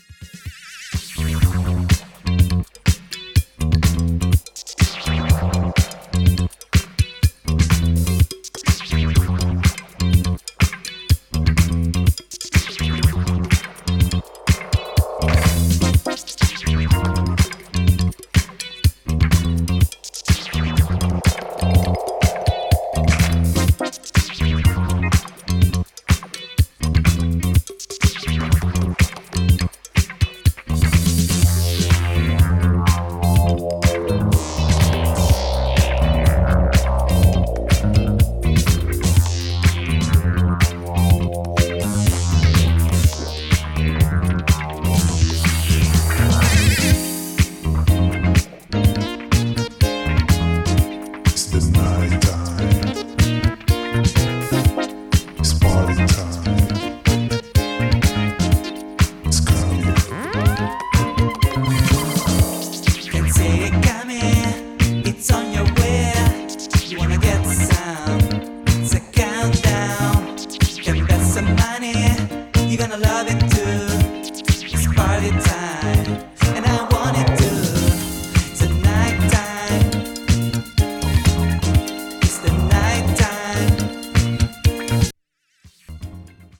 今回は、80'sテイストのエレクトリック・ファンク/ブギーを適度にルーズなテンションで展開。